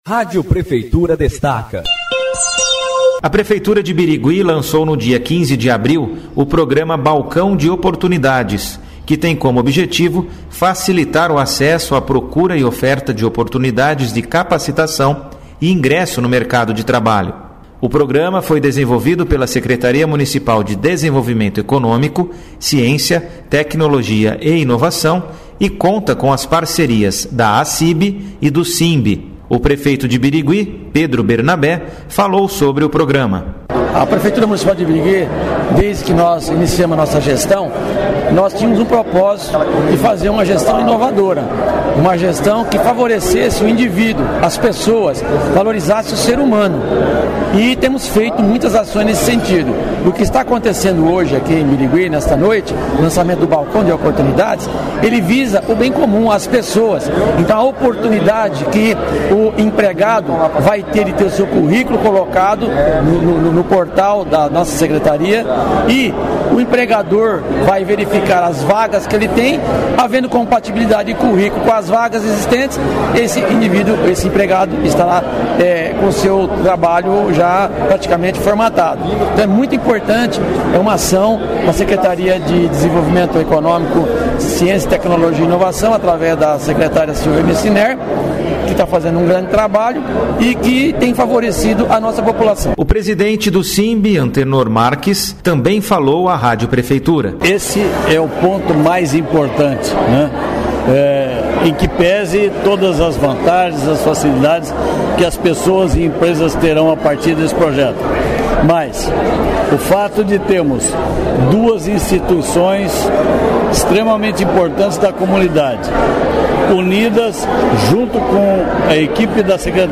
O lançamento oficial ocorreu no salão da Acib, que recebeu diversas autoridades e convidados.
Sonora: